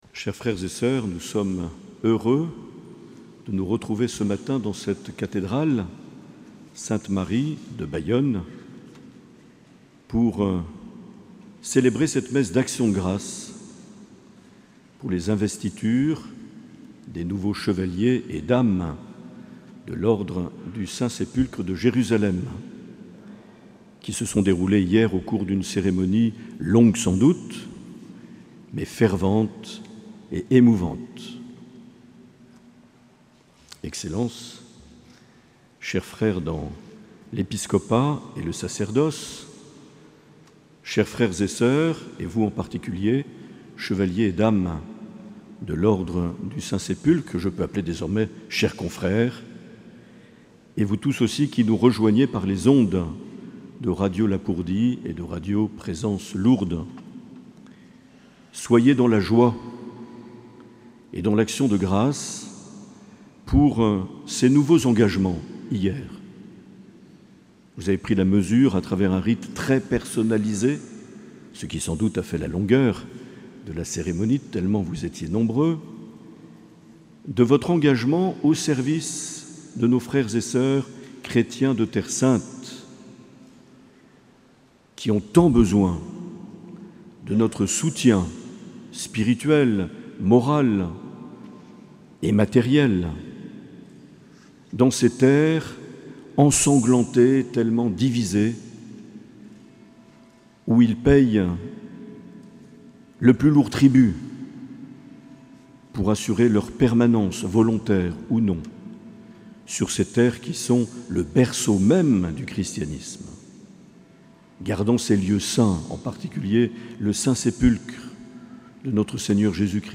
Accueil \ Emissions \ Vie de l’Eglise \ Evêque \ Les Homélies \ 26 septembre 2021 - Messe d’action de grâce avec l’Ordre équestre du (...)
Une émission présentée par Monseigneur Marc Aillet